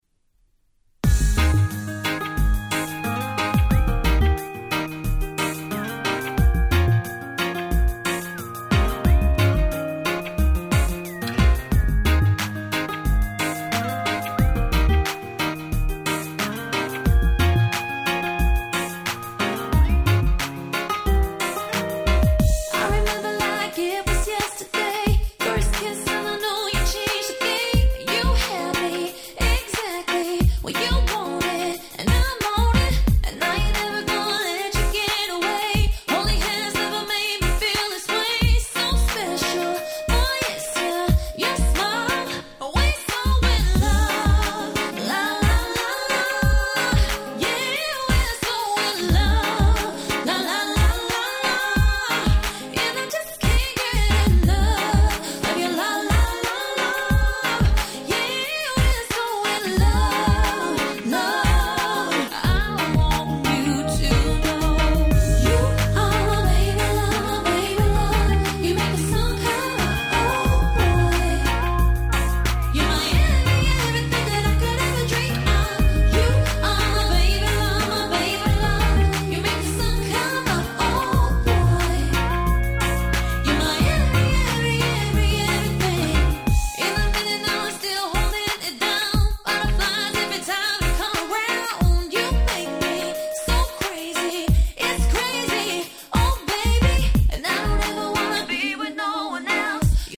Lovers Reggae ラバーズレゲエ